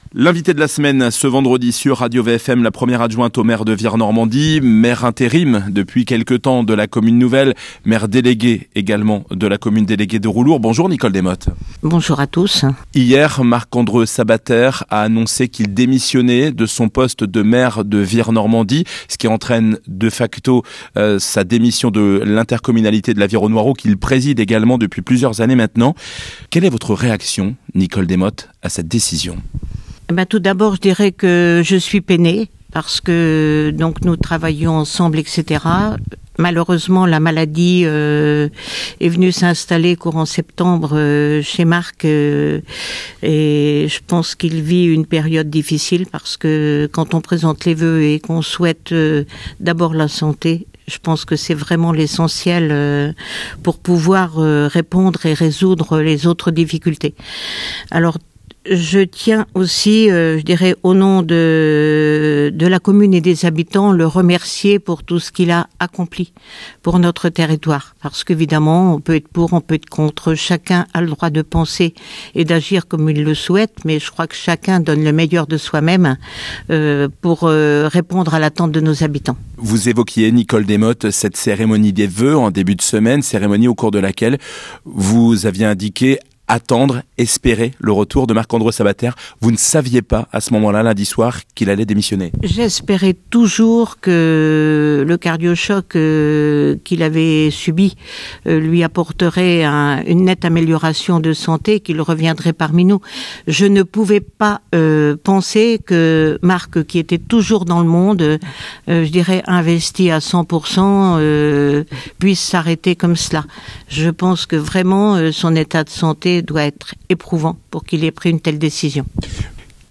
Nicole Desmottes Nicole Desmottes , maire par intérim de Vire Normandie, était l’invitée de la semaine ce vendredi sur Radio VFM .